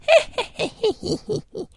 书呆子包 " 书呆子笑2
描述：书呆子笑了。